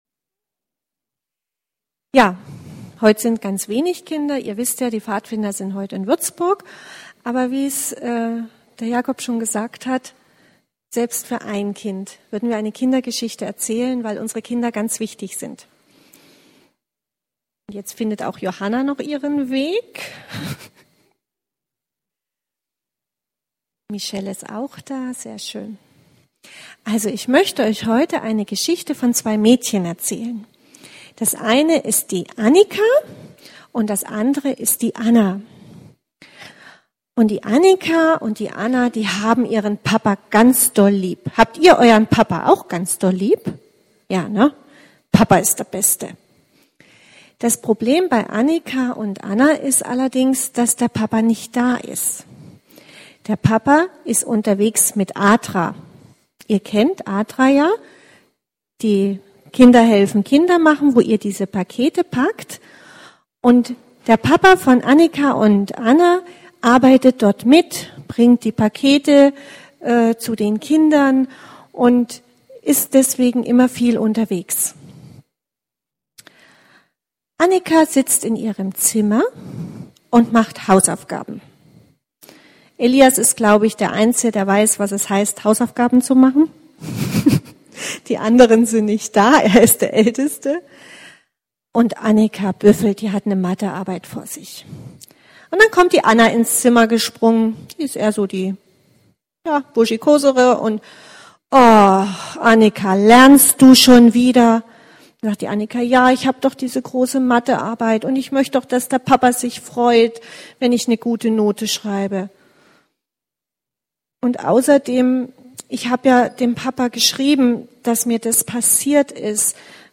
Abendmahlspredigt 16.11.19